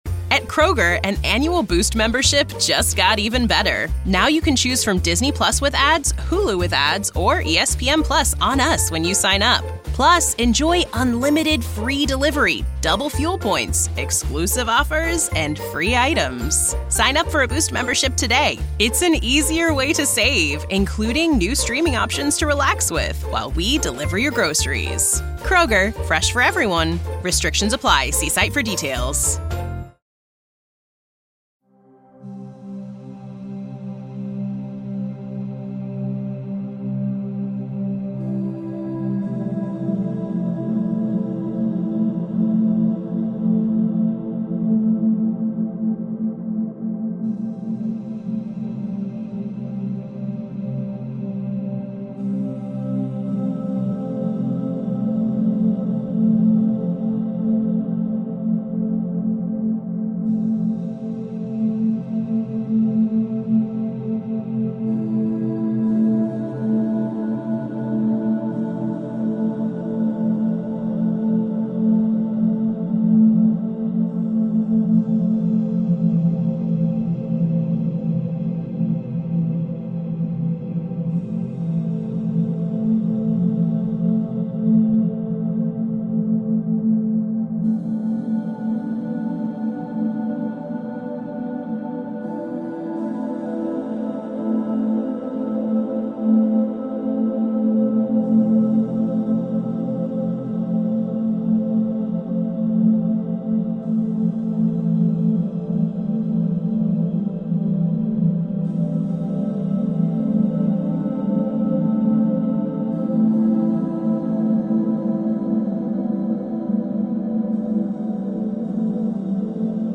Hypnosis and relaxation ｜Sound therapy
Here is a wonderful dreamland to help sleep and relax.